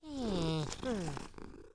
Npc Catpurr Sound Effect
Download a high-quality npc catpurr sound effect.
npc-catpurr.mp3